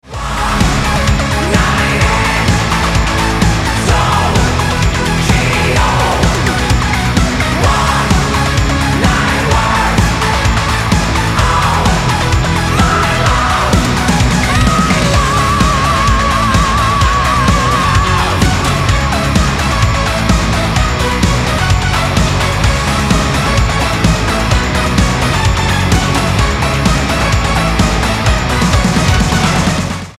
• Качество: 320, Stereo
мужской голос
громкие
жесткие
Драйвовые
электронная музыка
synth metal